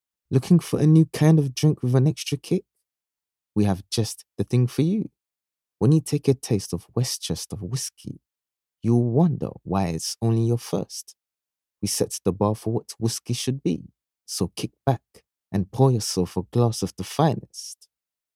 English (Caribbean)
Adult (30-50) | Yng Adult (18-29)